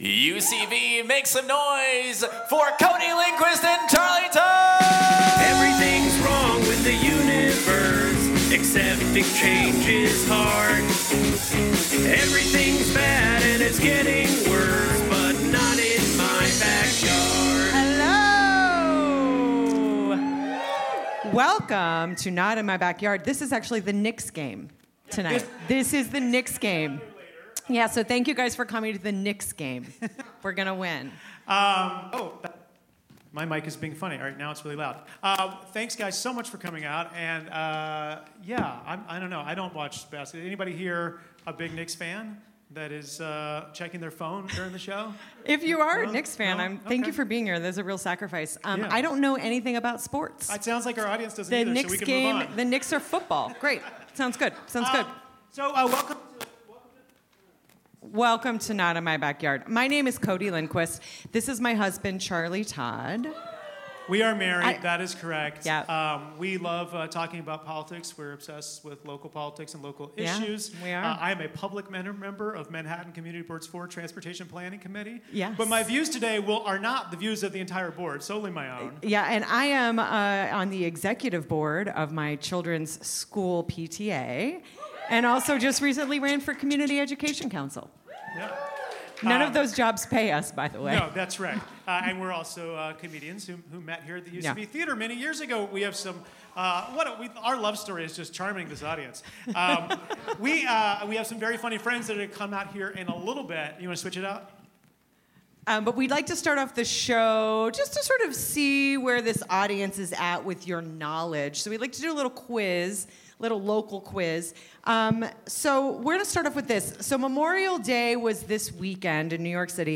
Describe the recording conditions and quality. Live form the UCB Theatre NY, we discuss Eric Adams' 7 cell phones, 4:00 AM last calls, and the ethics of destroying Canandian goose eggs.